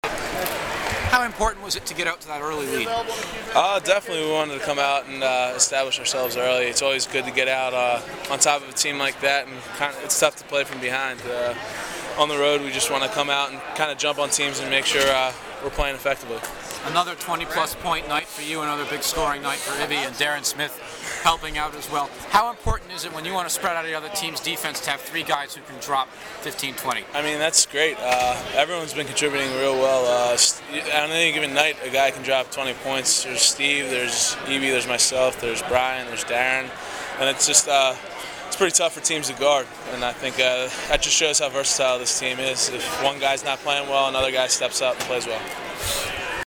Postgame audio